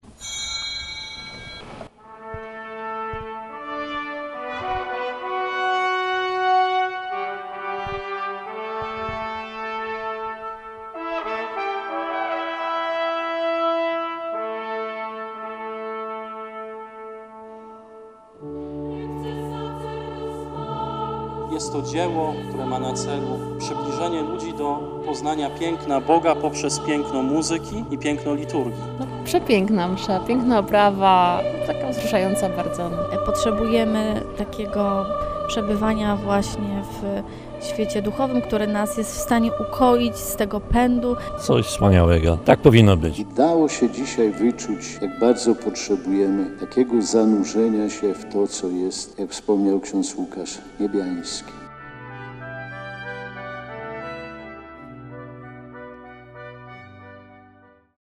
Pierwszym punktem była uroczysta Msza święta z oprawą muzyczną przygotowaną przez organizatorów. Celebrował ją biskup warszawsko-praski Romuald Kamiński.